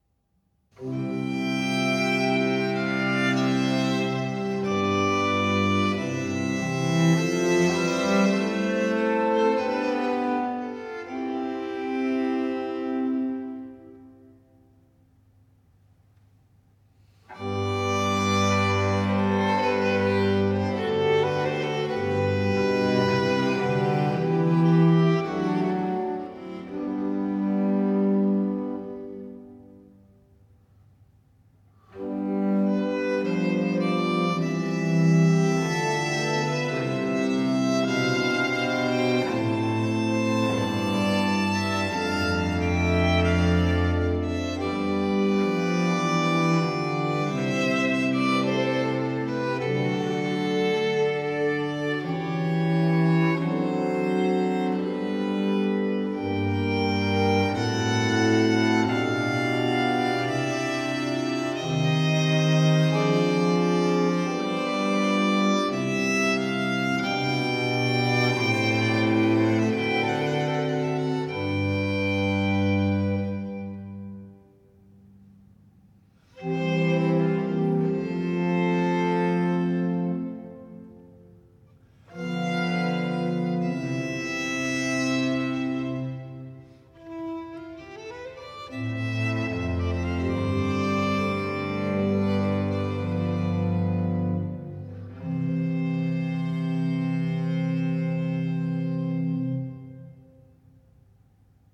Largo